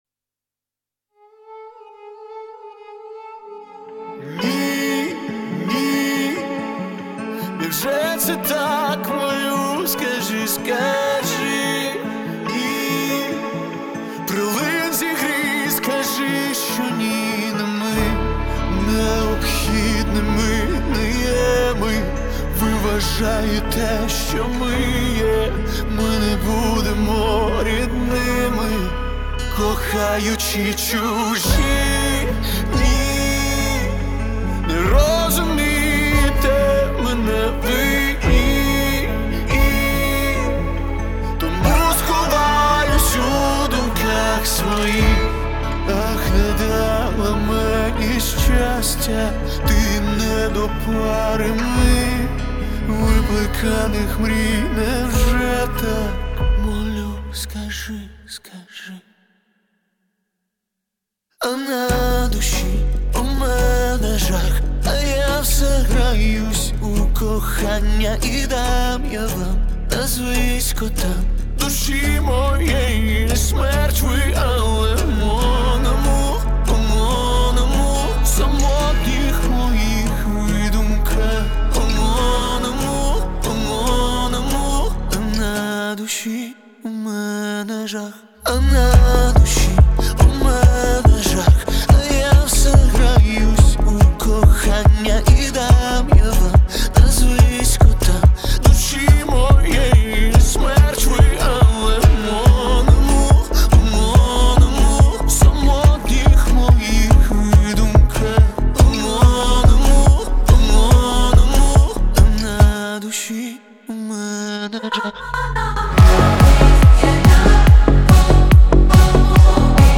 • Жанр: Українська музика